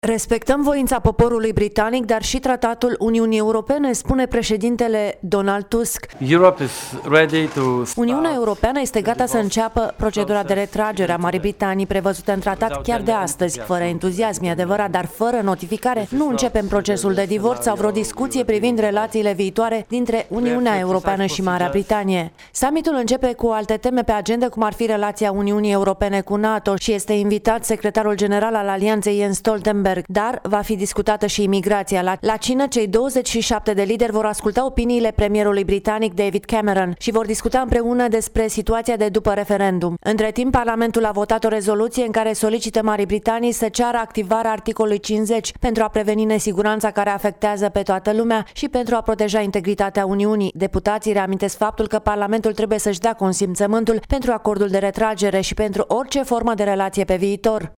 Cu detalii, din Bruxelles